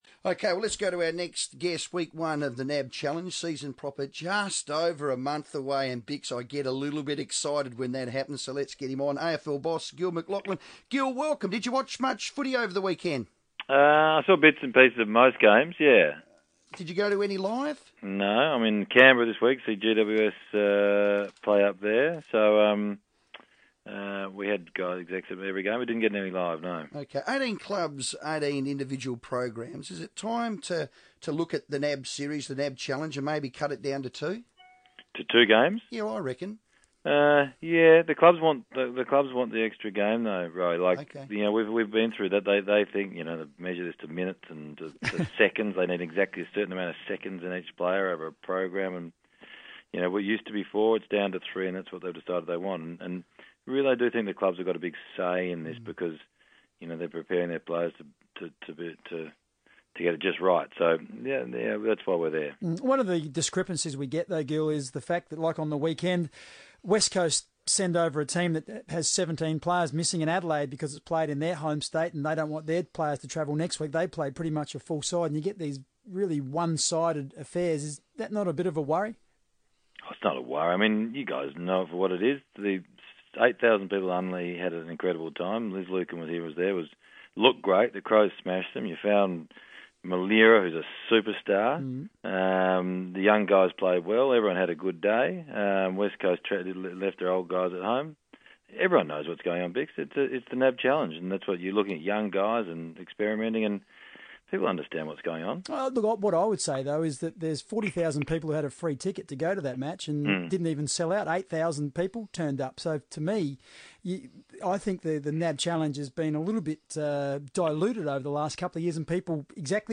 AFL CEO Gillon McLachlan on FIVEaa